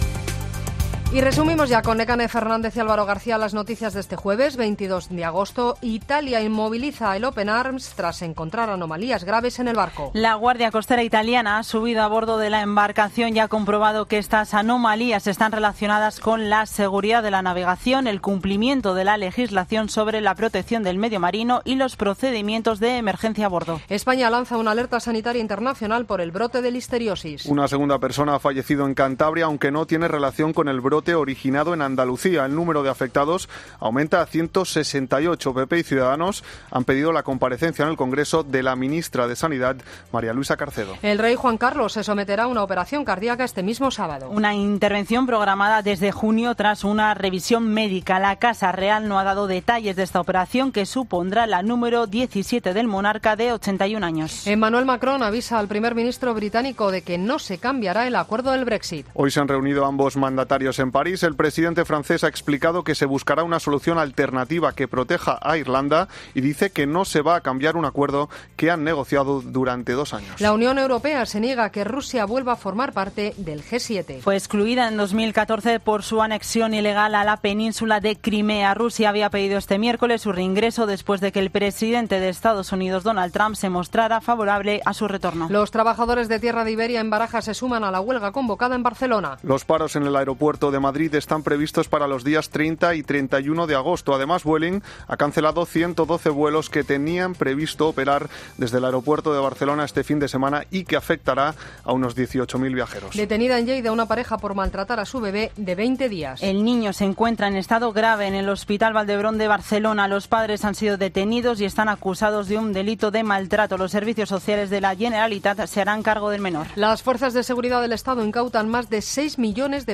Boletín de noticias de COPE del 22 de agosto de 2019 a las 20.00 horas